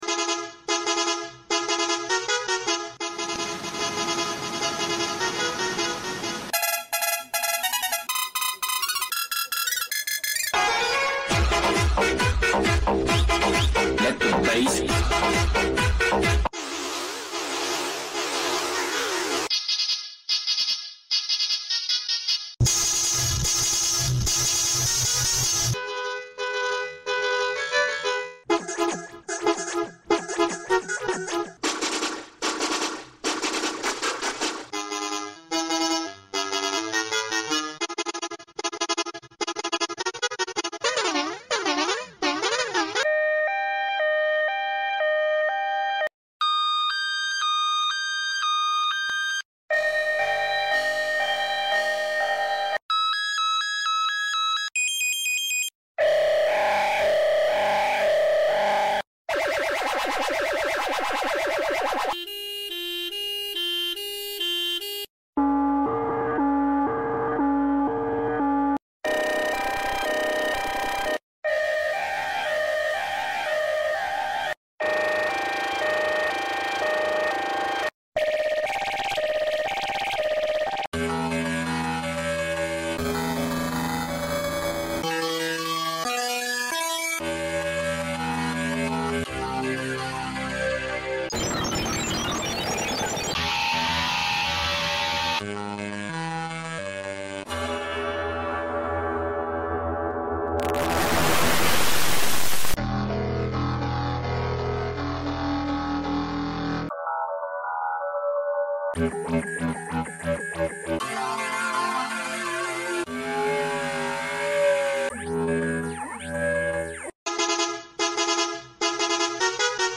72 Transport Sound Variations In sound effects free download